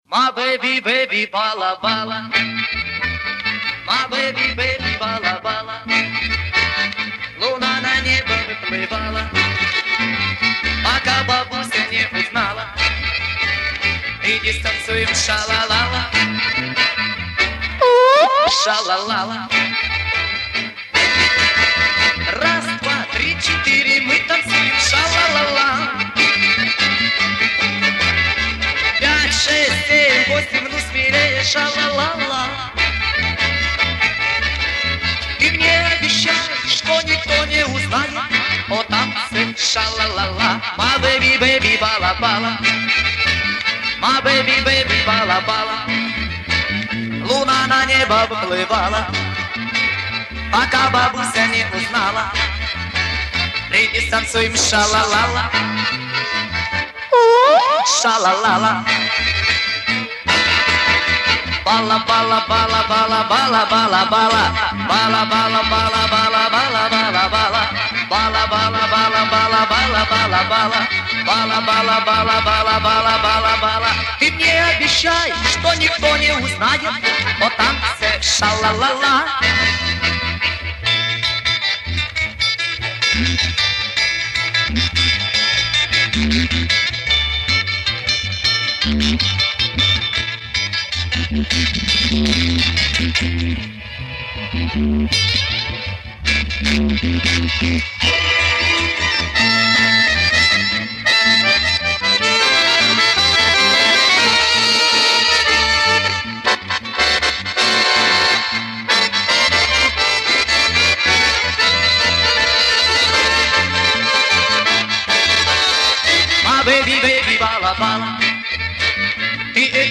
И дворовая перепевка на русском языке